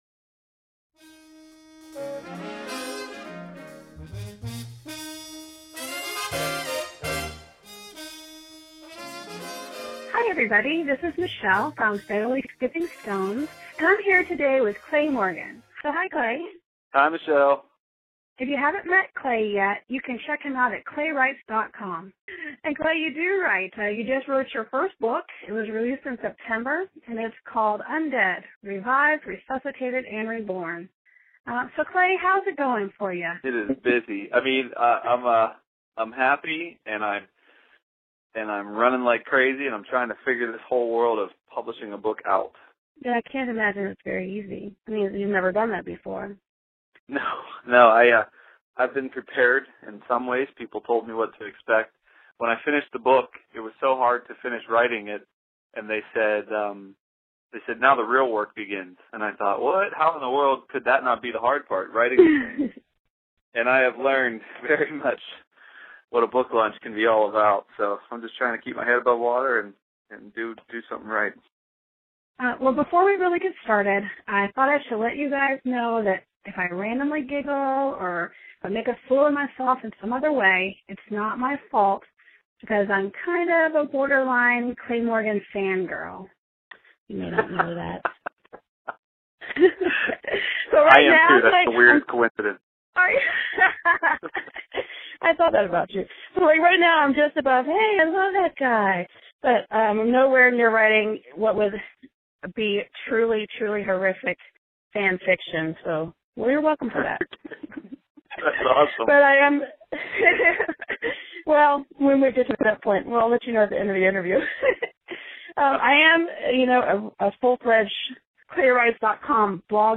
Here is part one of the interview; I hope the rest of you enjoy it as much as I did.